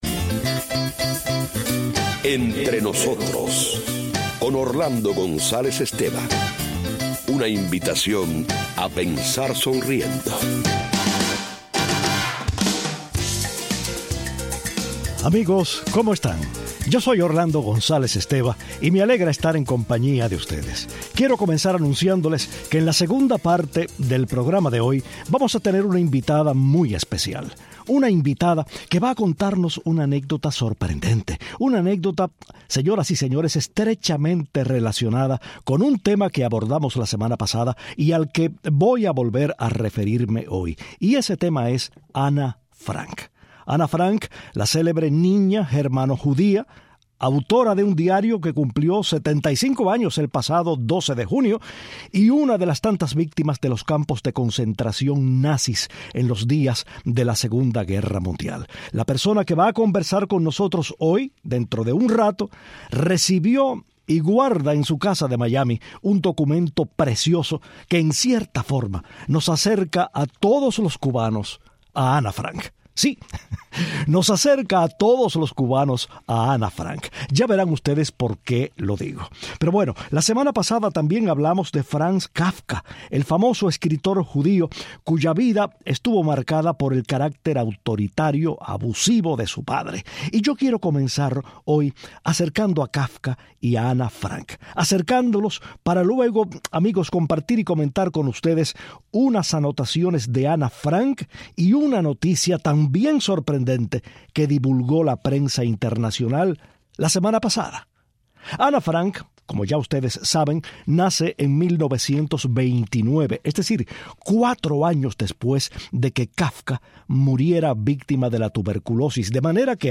Hoy entrevistamos